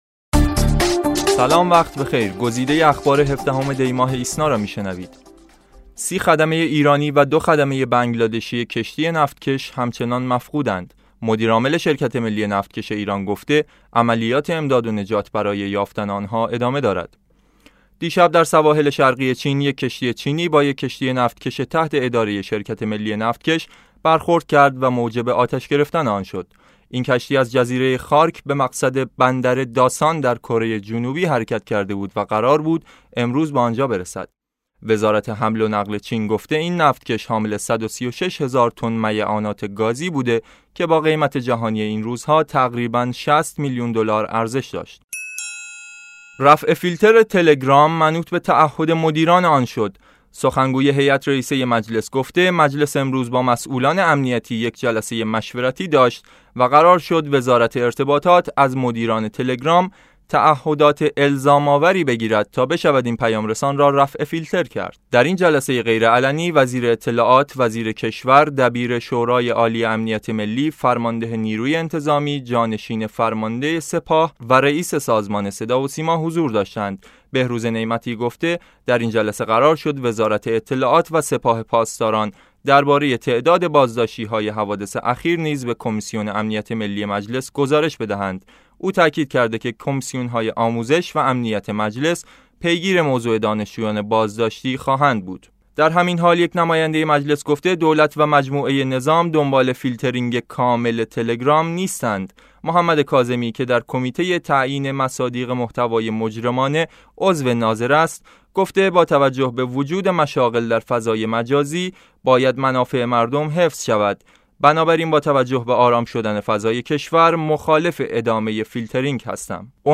صوت / بسته خبری ۱۷ دی ۹۶